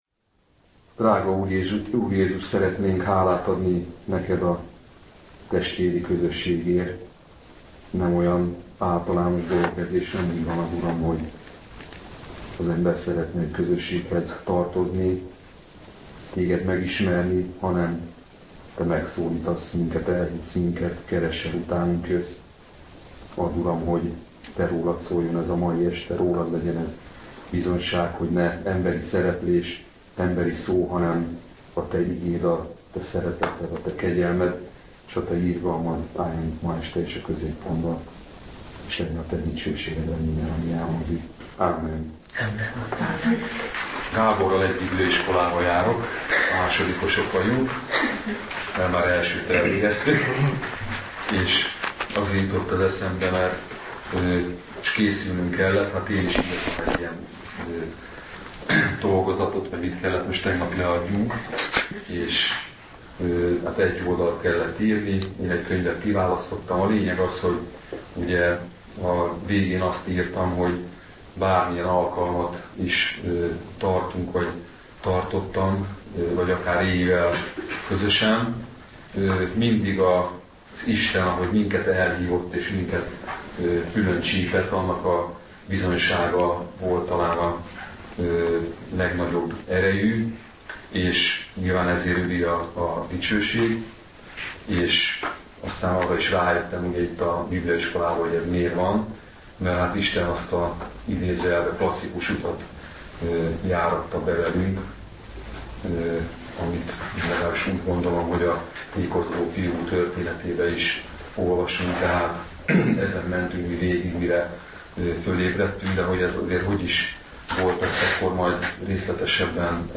Bizonyságtétel